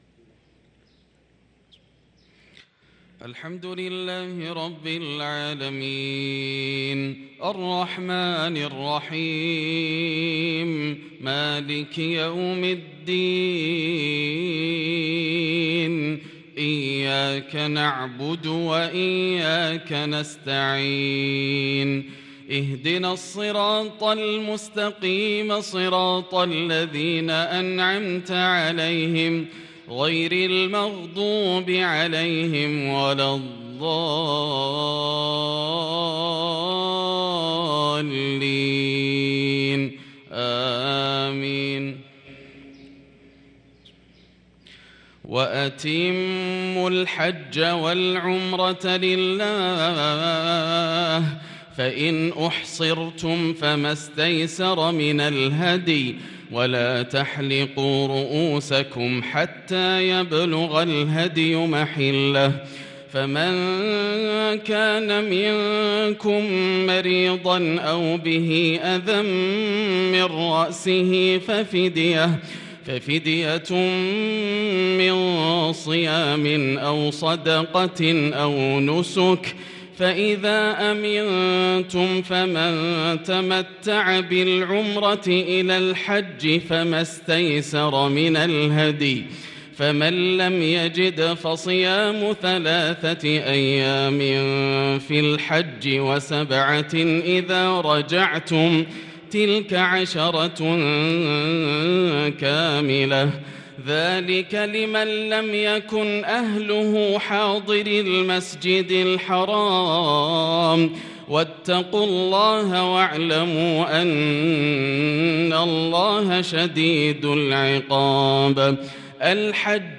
صلاة الفجر للقارئ ياسر الدوسري 8 ذو الحجة 1443 هـ
تِلَاوَات الْحَرَمَيْن .